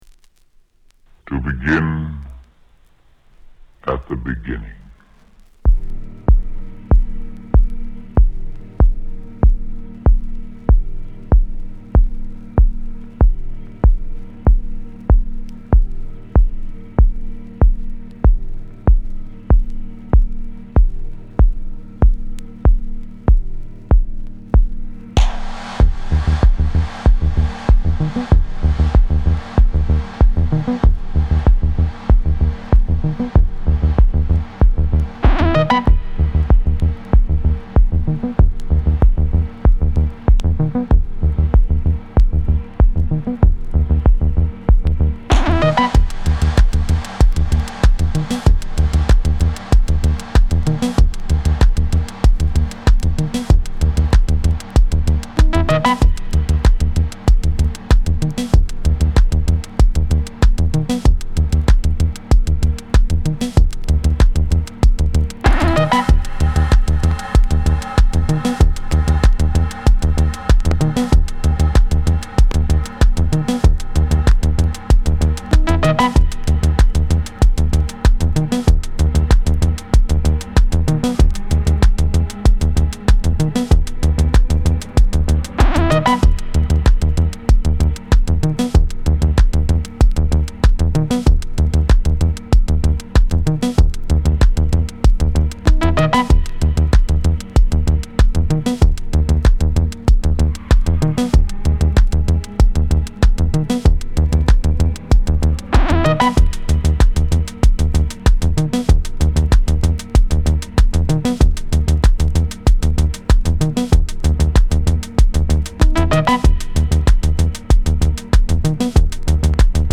Pitched Down Mix